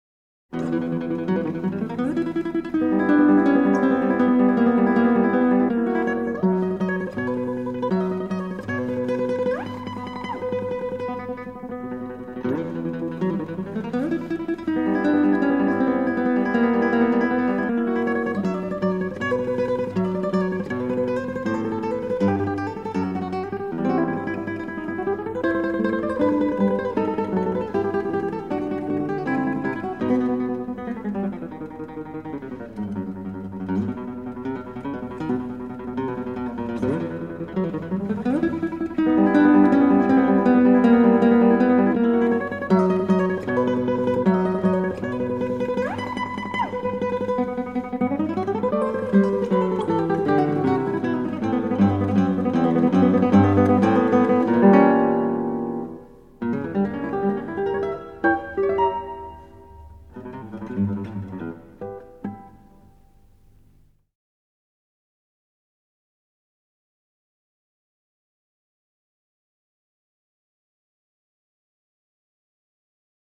Gitarrenmusik des 19. & 20. Jh.